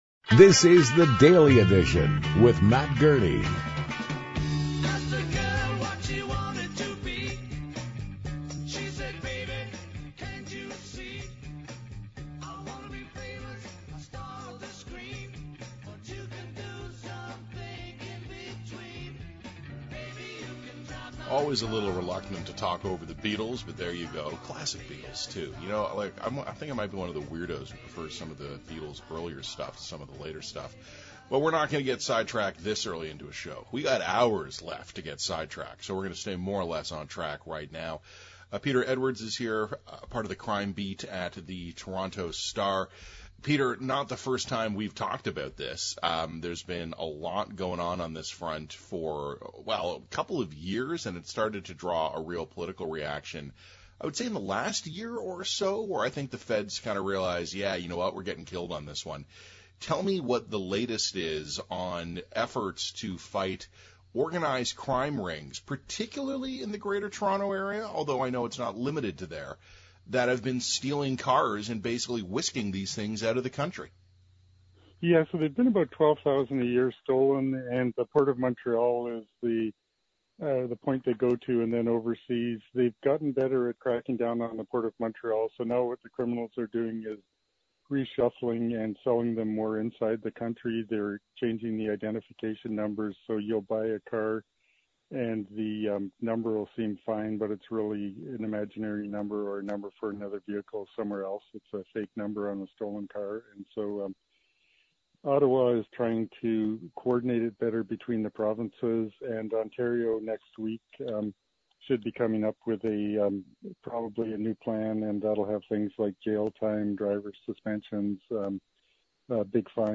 SiriusXM interview about how politics enters into fight against auto theft